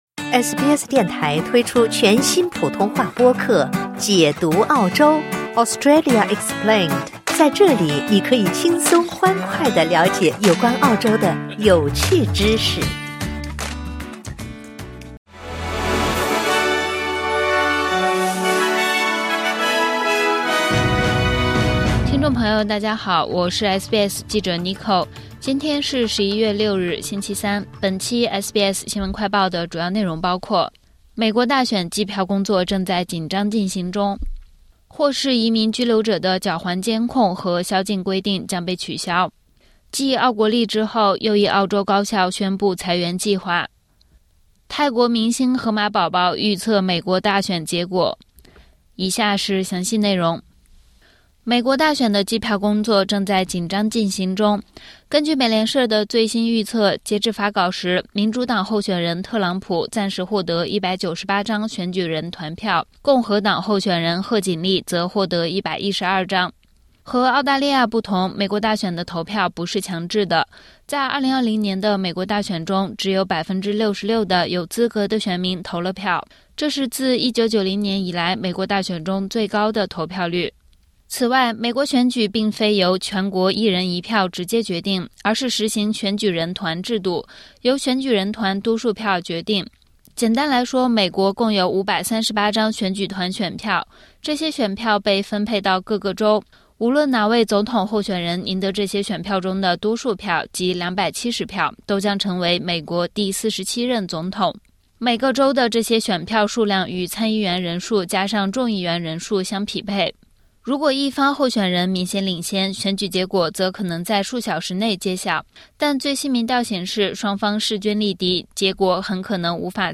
【SBS新闻快报】美国大选计票正在进行中 特朗普暂时领先